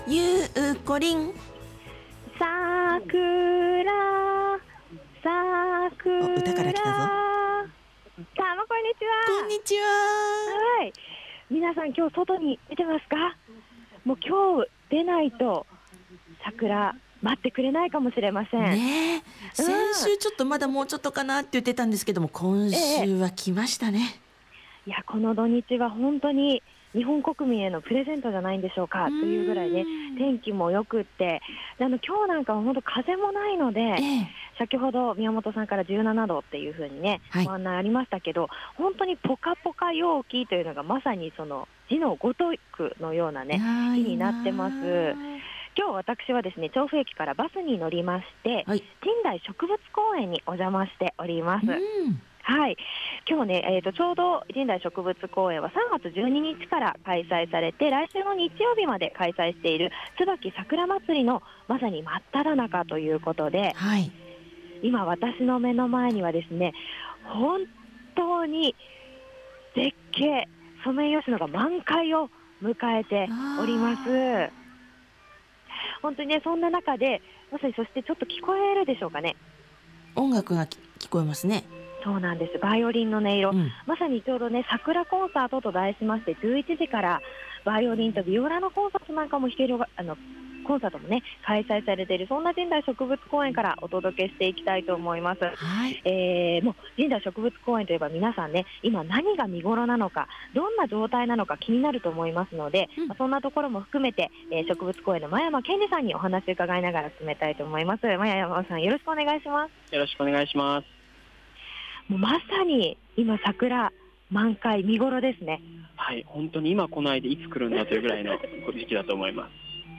ソメイヨシノが満開の見ごろを迎えている、神代植物公園にお邪魔してきましたよ～！